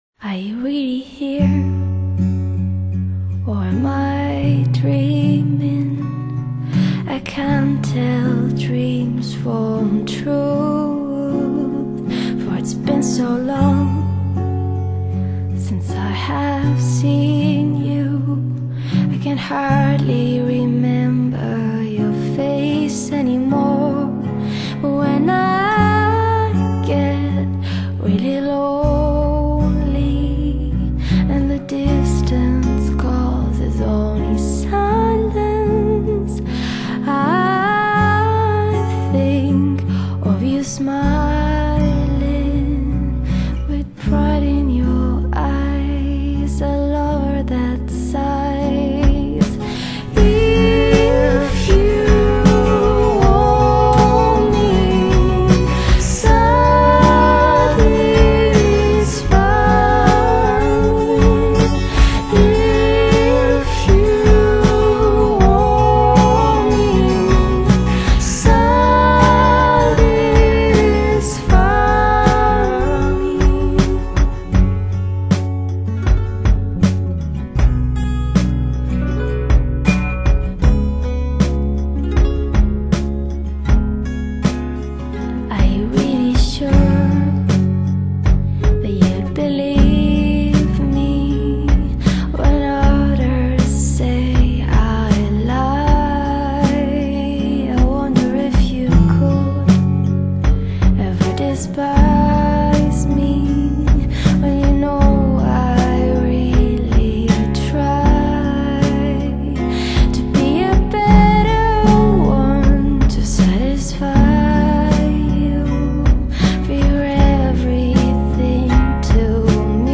傷感聆聽